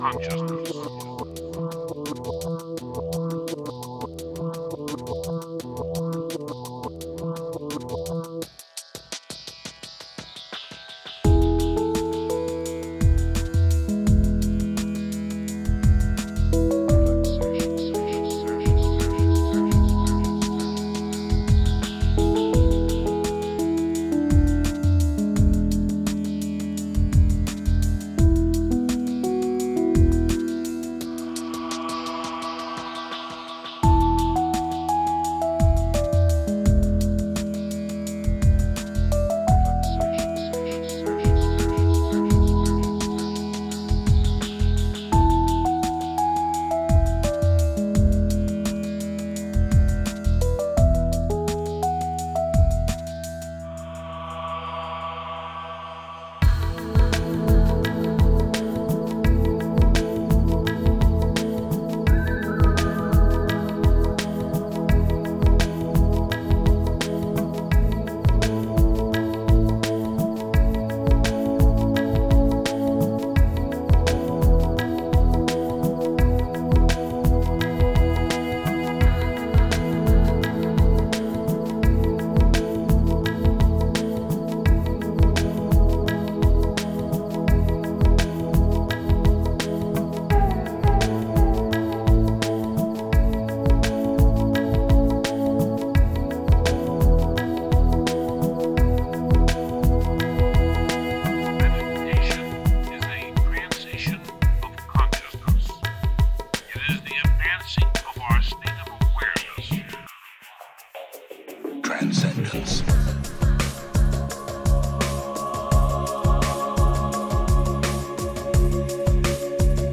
Genre: New Age, Enigmatic.